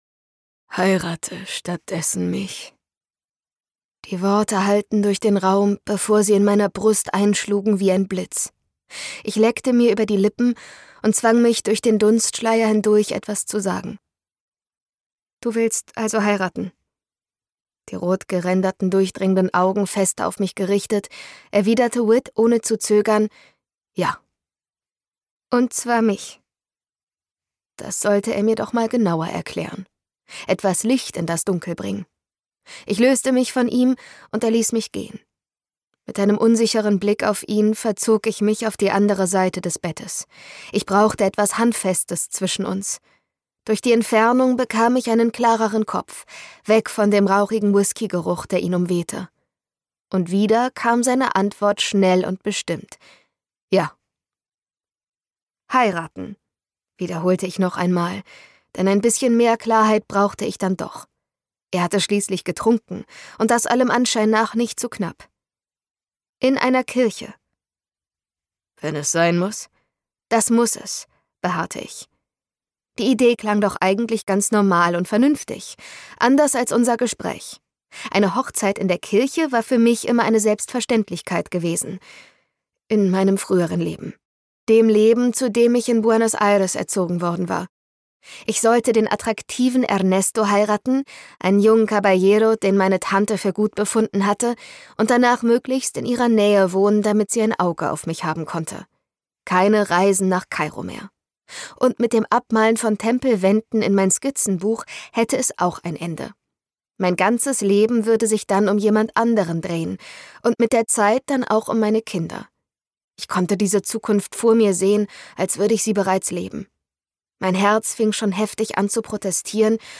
Zwischen ihren Stimmenfarben sprühen magische Funken.
Gekürzt Autorisierte, d.h. von Autor:innen und / oder Verlagen freigegebene, bearbeitete Fassung.